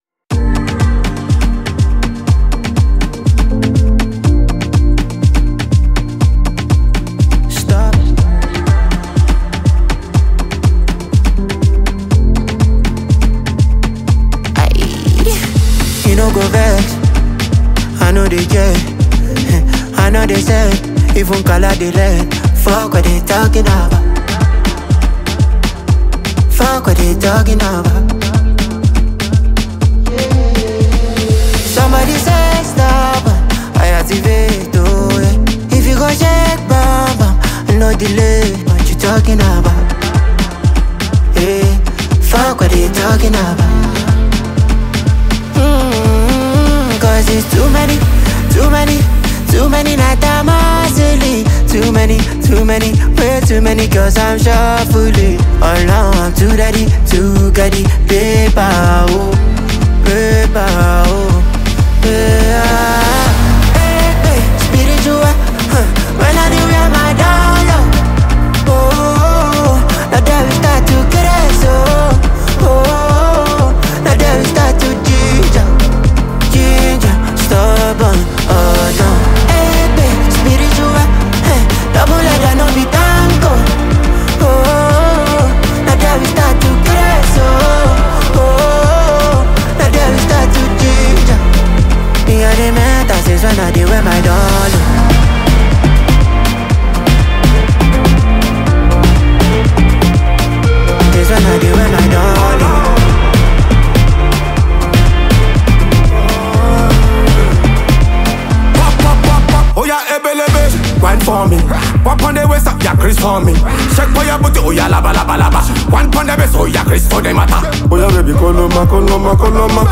From smooth melodies to hard-hitting rhythms